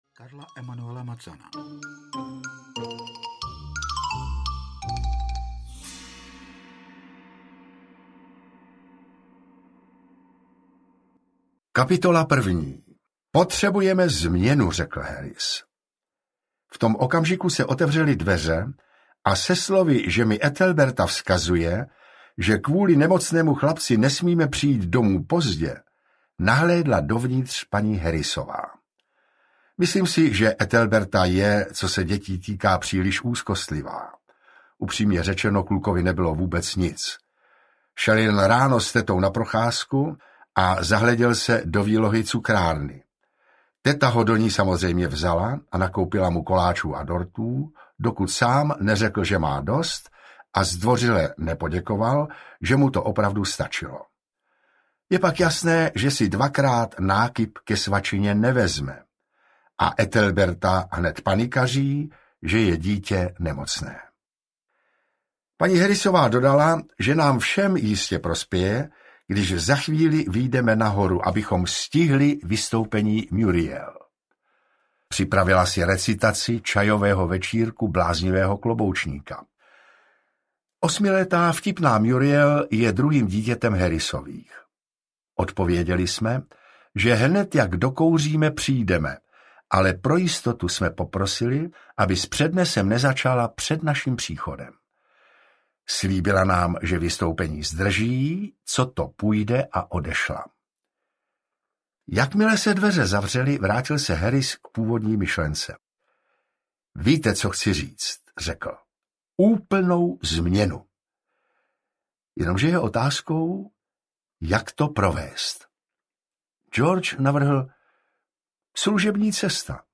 Tři muži na toulkách audiokniha
Ukázka z knihy
• InterpretOtakar Brousek st.